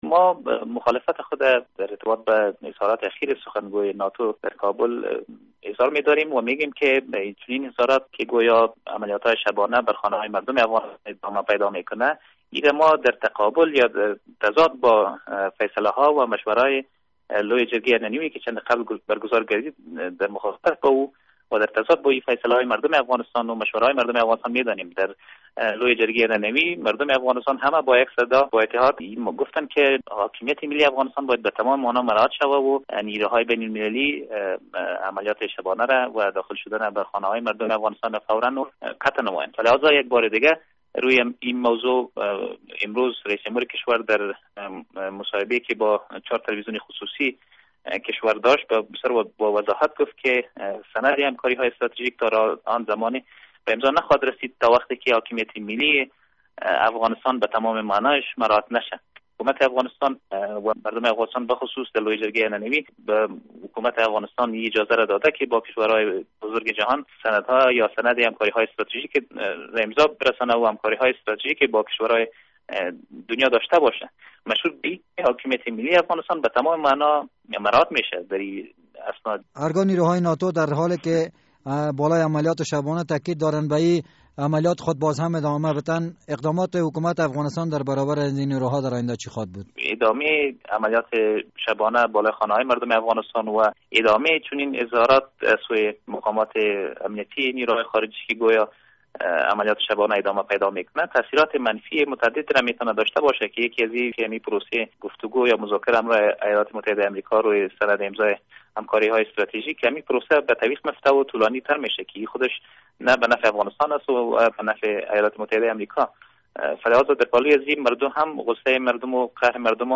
مصاحبه در مورد ادامهء عملیات شبانه از سوی نیروهای ناتو